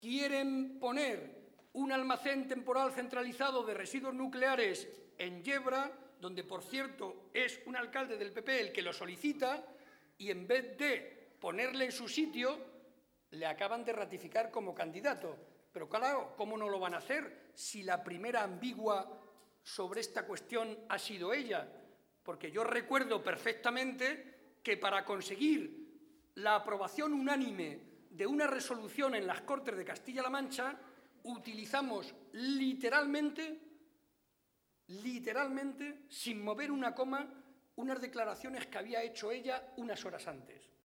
Momento del acto público del PSOE celebrado en Alovera.